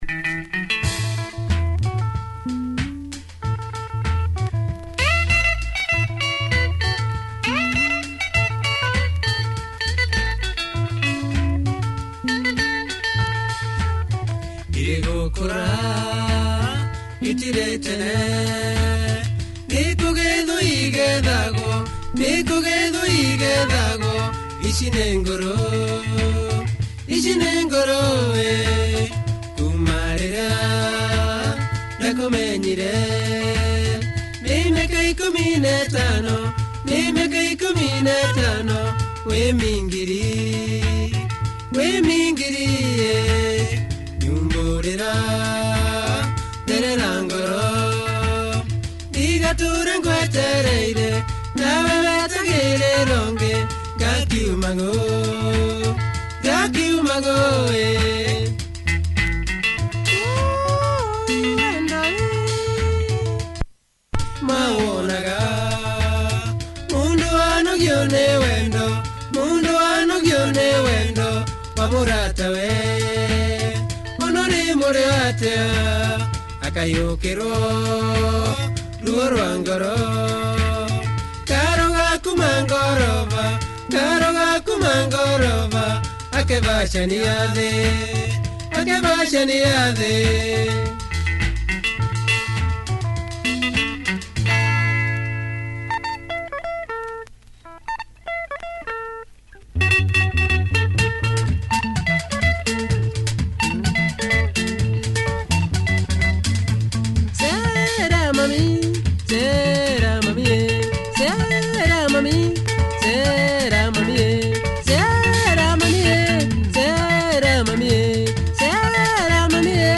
Club potential, check audio!